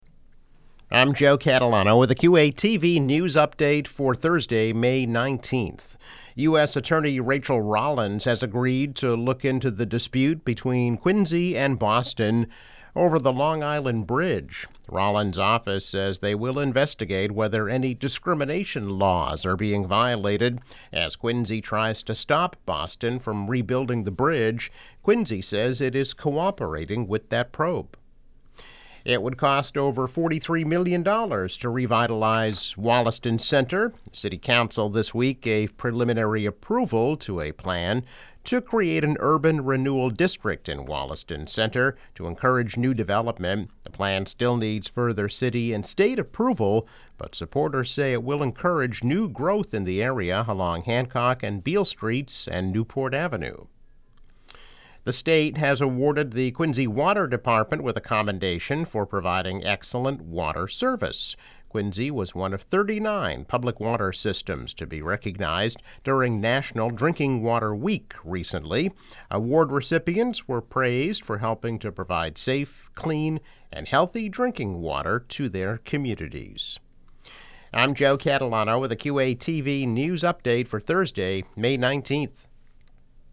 News Update - May 19, 2022